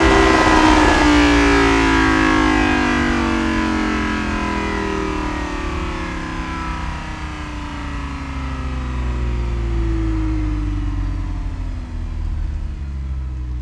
rr3-assets/files/.depot/audio/Vehicles/v10_03/v10_03_decel.wav
v10_03_decel.wav